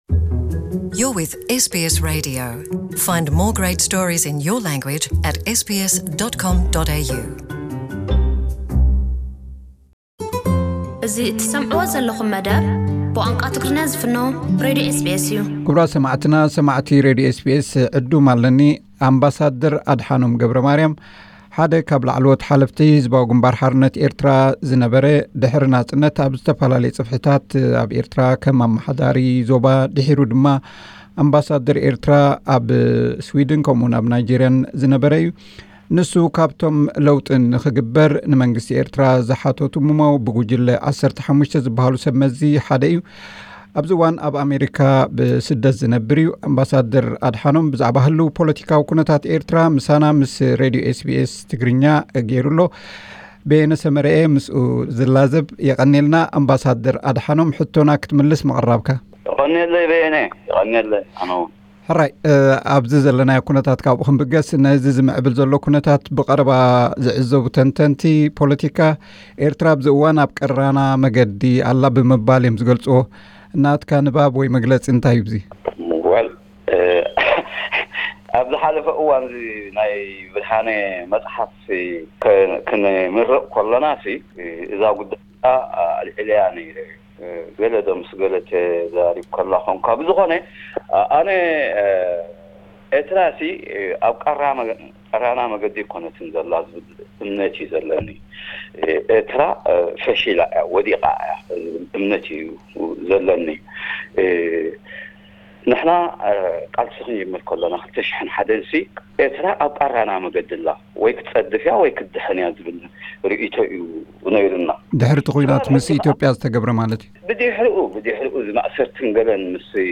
SBS Tigrinya : Interview with AdHanom Gebremariam: